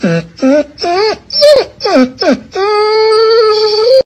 Whining Dog Sound Effect Download: Instant Soundboard Button
Whining Dog Sound Button - Free Download & Play